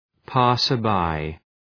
Προφορά
{,pæsər’baı}